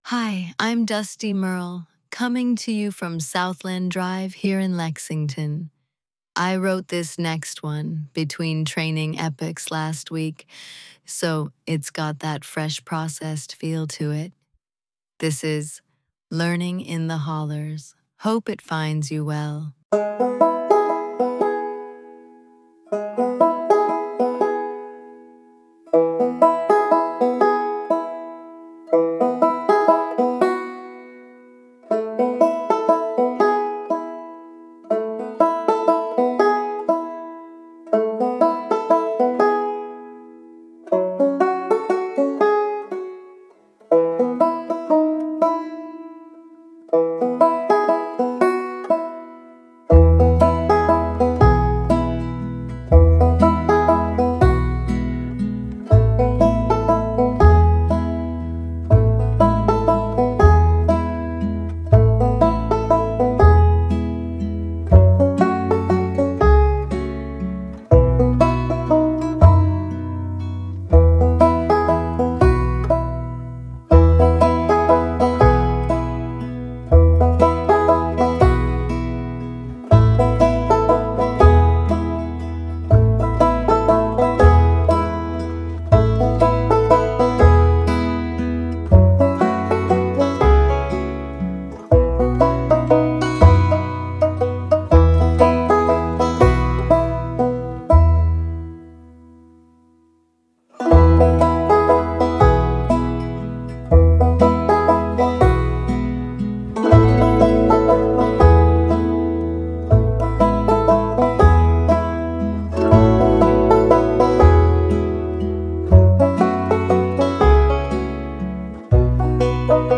This transcript is from a recent on-air segment.
Voice synthesis via ElevenLabs; script via Claude.